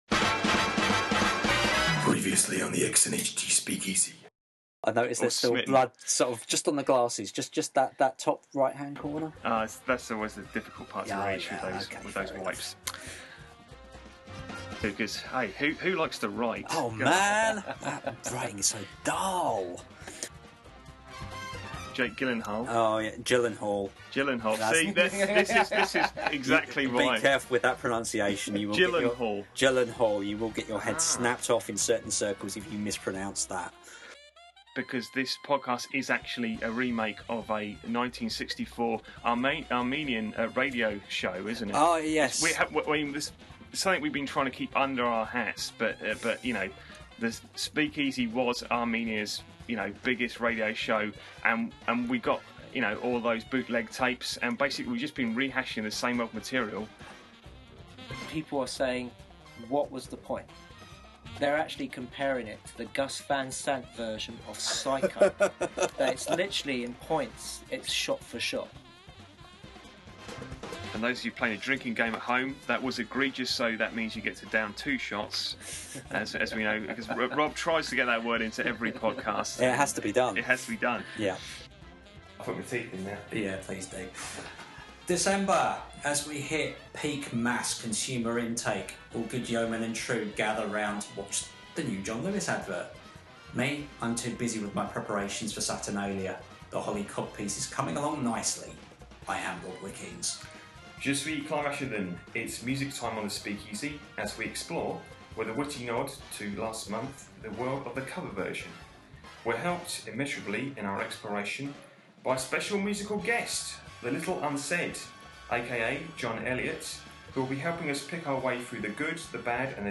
Endearingly shambolic is the way we roll, wise guy!
Note the professionalism of the studio setup.